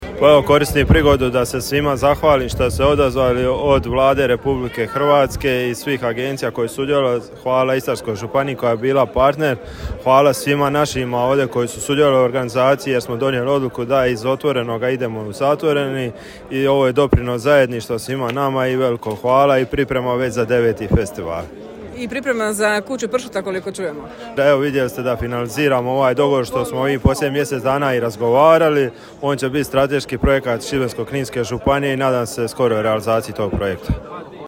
Večeras je u Drnišu svečano otvoren 8. Međunarodni festival pršuta, u organizaciji Turističke zajednice Grada Drniša te Grada Drniša.
Evo izjava:
Gradonačelnik Drniša Tomislav Dželalija